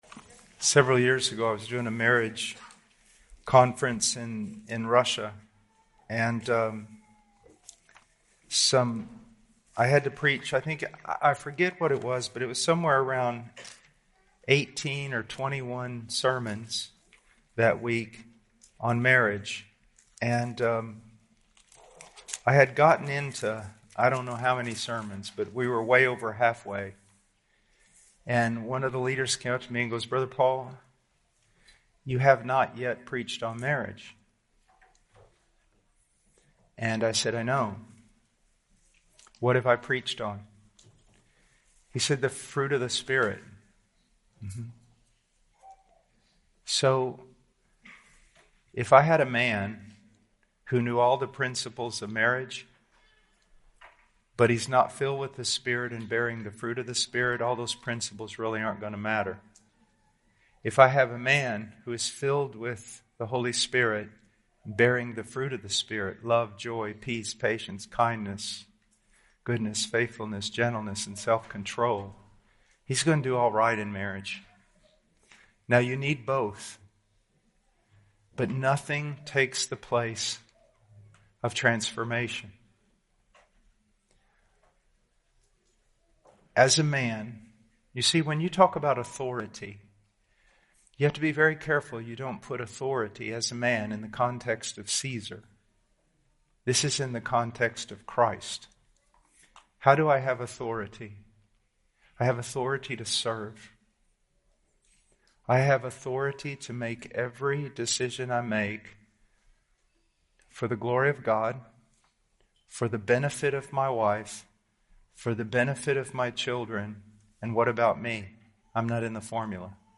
This was a spontaneous extra session from the 2021 Fellowship Conference.